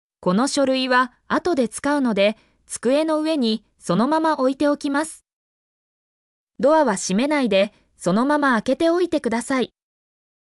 mp3-output-ttsfreedotcom-76_gxHrjZMI.mp3